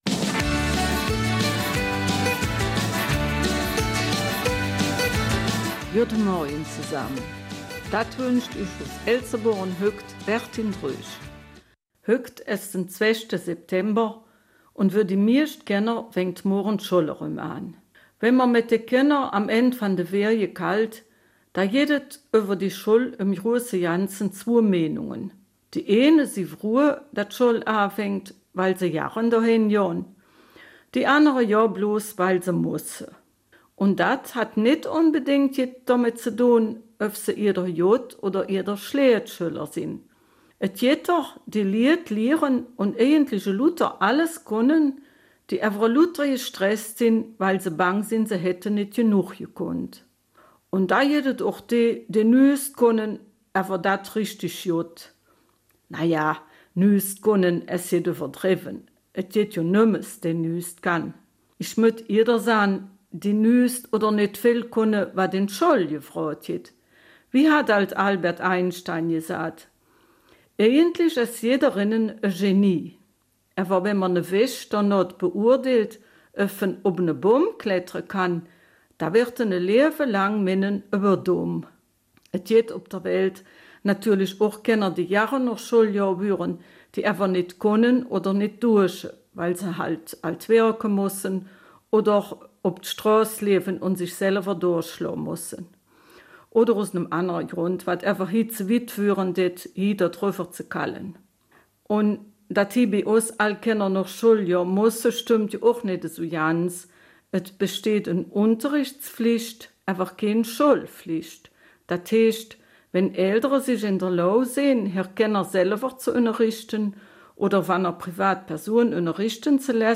Eifeler Mundart zum Schulanfang am 2. September